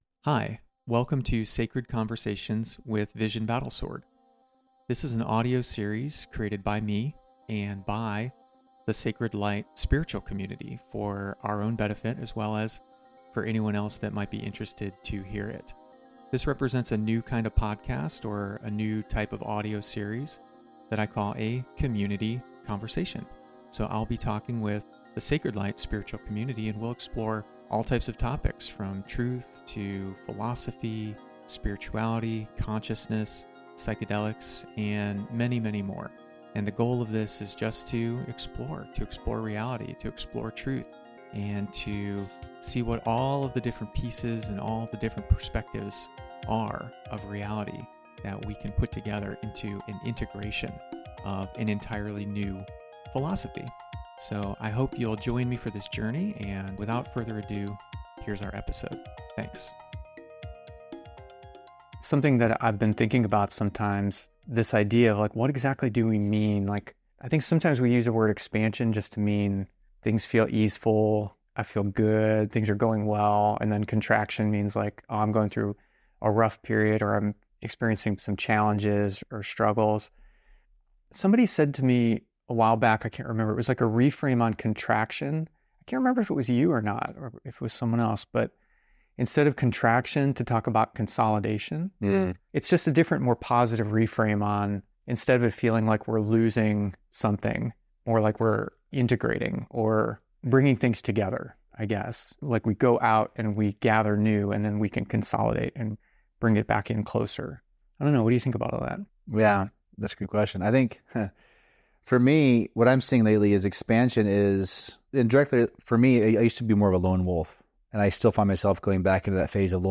conversation22-expansion.mp3